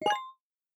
biometric_register_complete.ogg